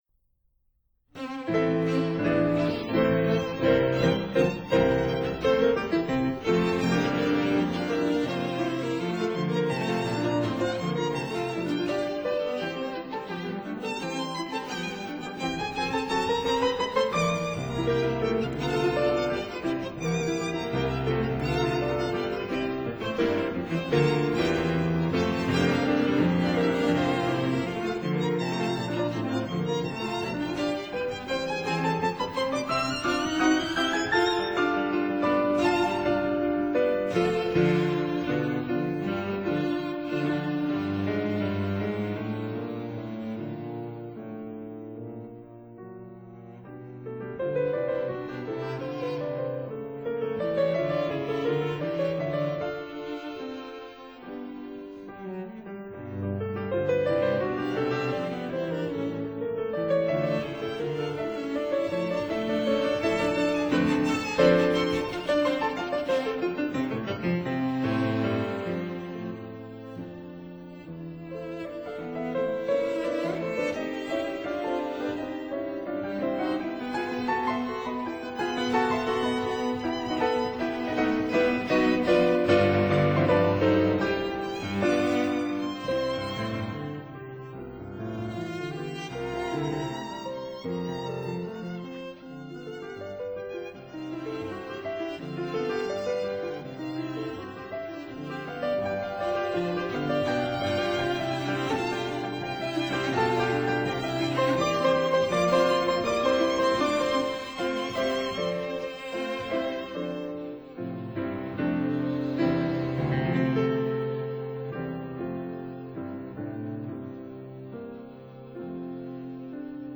violin
cello
piano